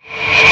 VEC3 Reverse FX
VEC3 FX Reverse 16.wav